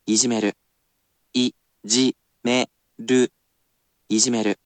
I personally sound out each word or phrase aloud for you to repeat as many times as you wish, and you can ask me to say it as many times as you wish.